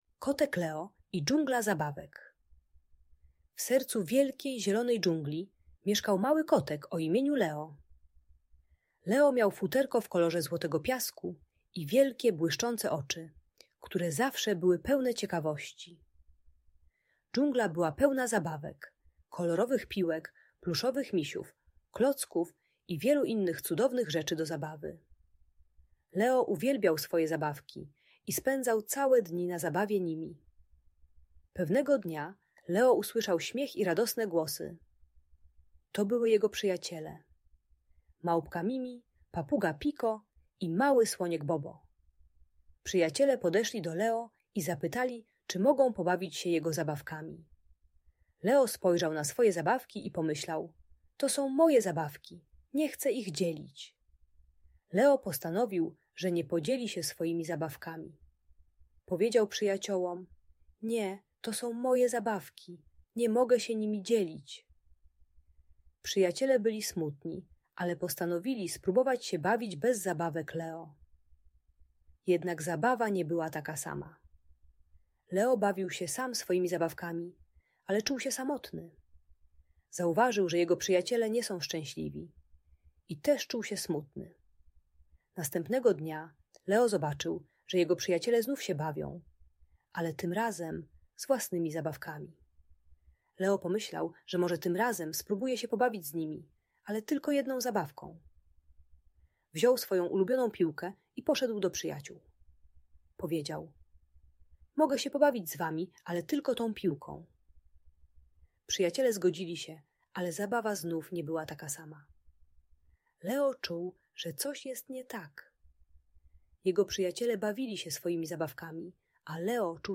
Historia o kotku Leo i dżungli zabawek - Audiobajka